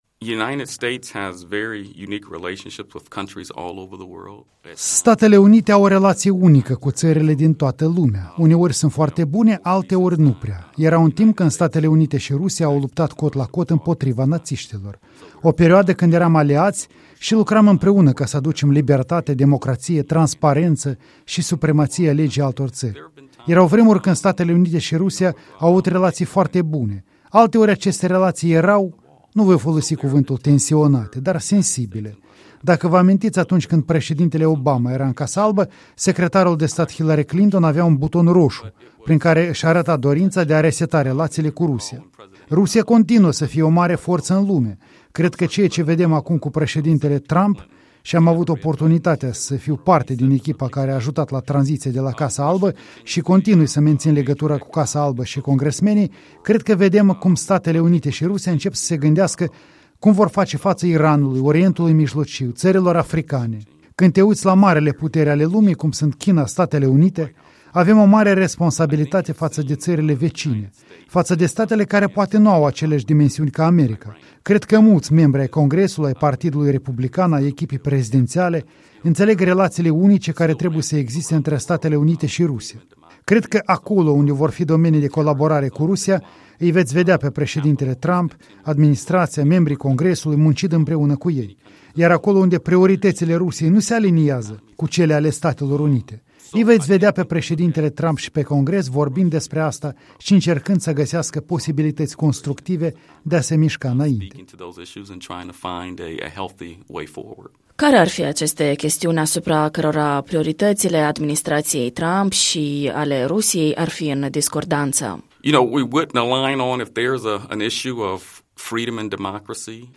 Un interviu despre relațiile americano-ruse cu un expert din Statele Unite, co-fondator al uneicompanii de consultanță și care a făcut o vizită la Chișinău.
Un expert în consultanță american în dialog cu Europa Liberă la Chișinău